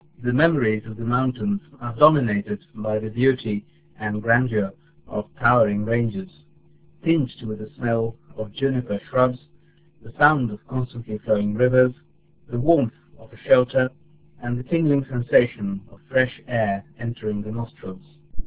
A traveler recalls a visit to the Parbati Valley.